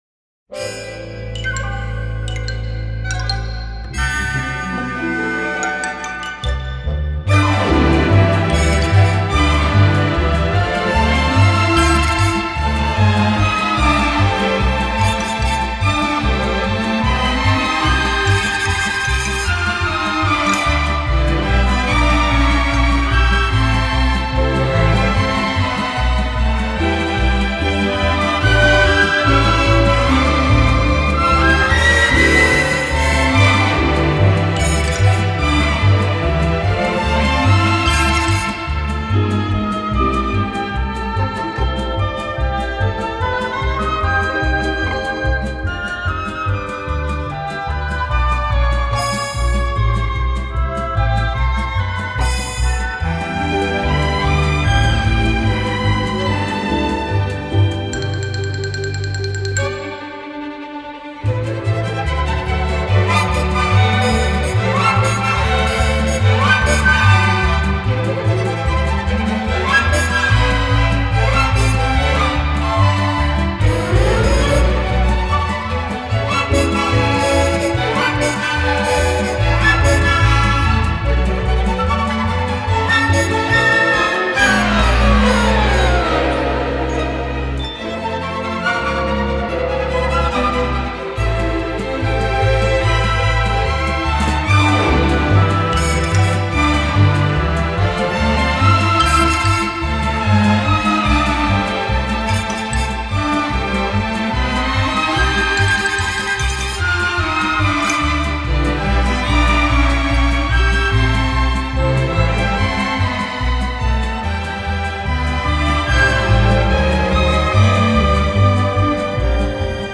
OLED SIIN ▶ muusika ▶ Lounge